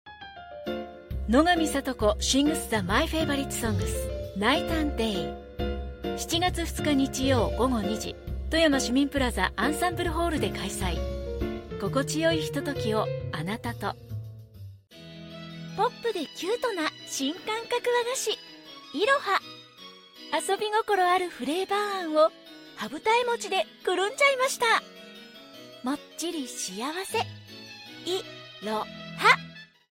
Voice-Age adult, youngAdult Voice-Style middle, light Country Japan Sex female Voice Probe Japanisch